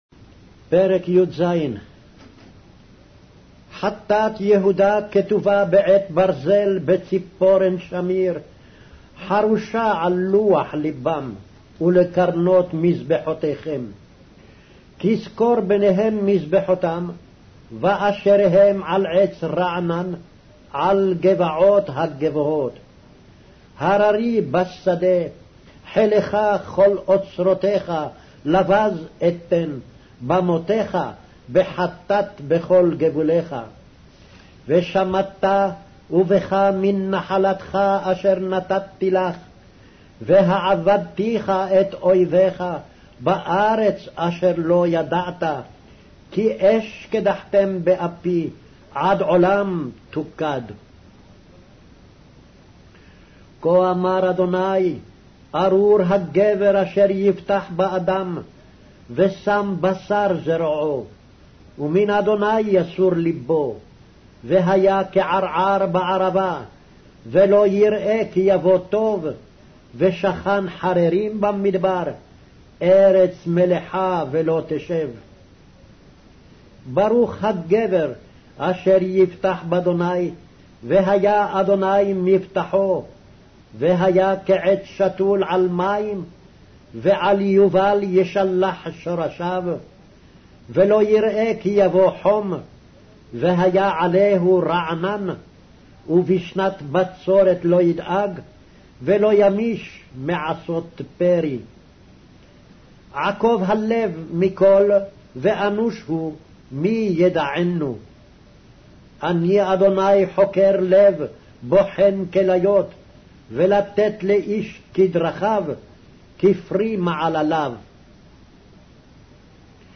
Hebrew Audio Bible - Jeremiah 44 in Gntbrp bible version